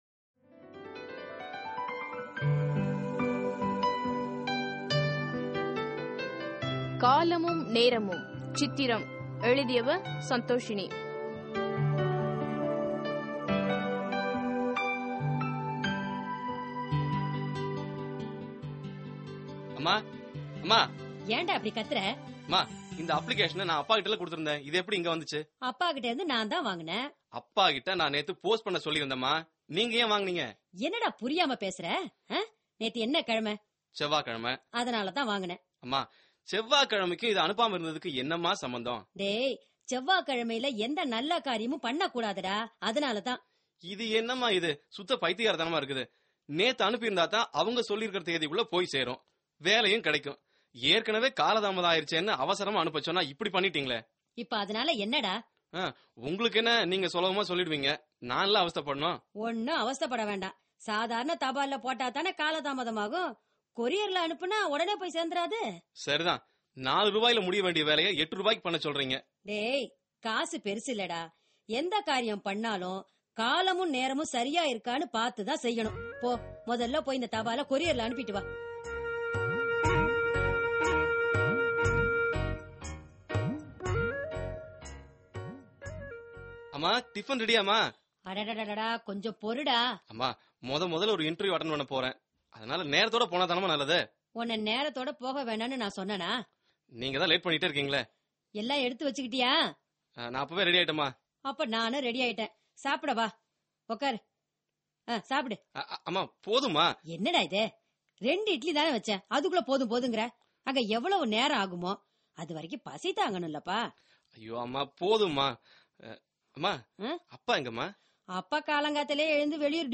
Directory Listing of mp3files/Tamil/Dramas/Social Drama/ (Tamil Archive)